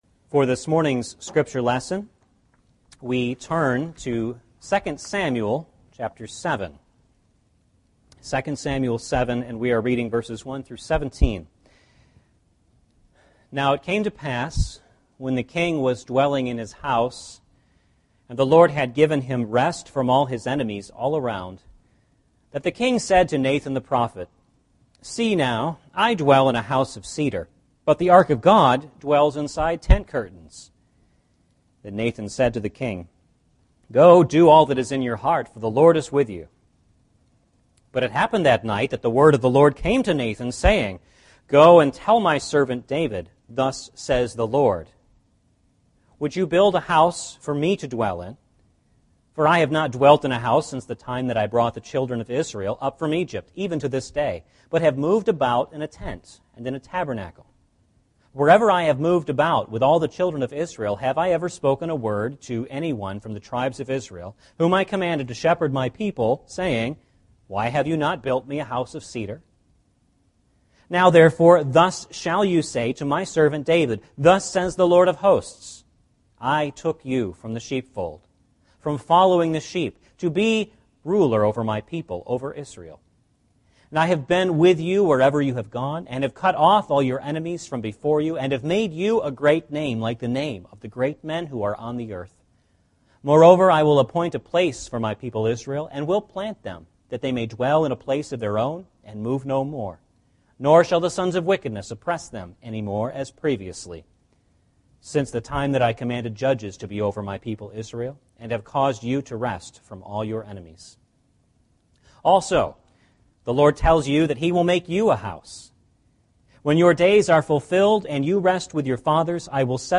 2 Samuel 7:1-17 Service Type: Sunday Morning Service Recording not available « WCF chapter 5